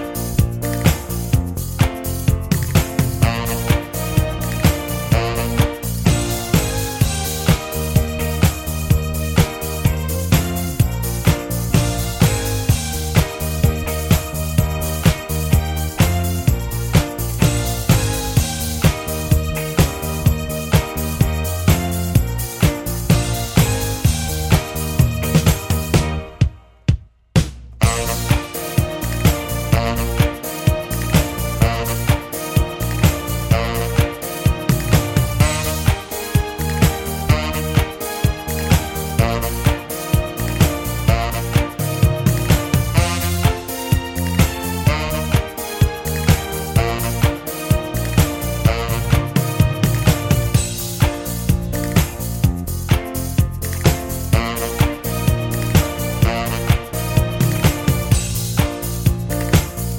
no Backing Vocals Disco 4:29 Buy £1.50